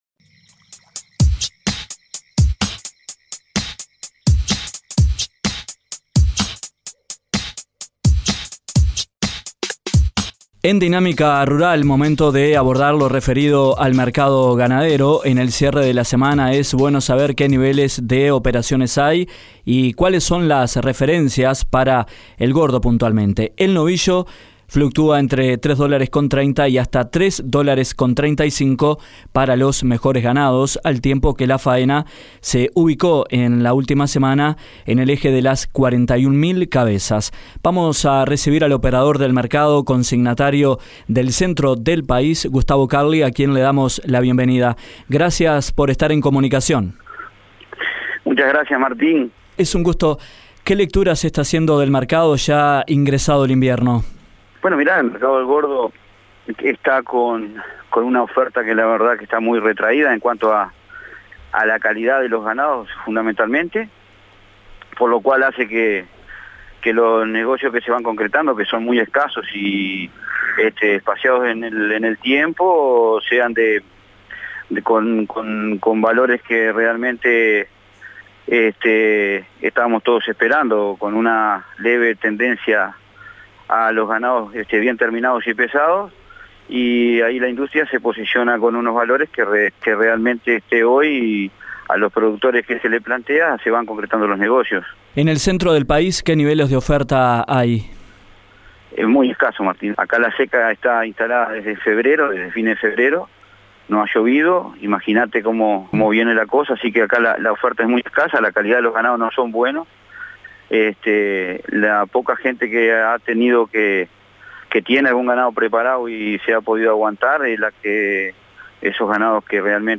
en diálogo mantenido con Dinámica Rural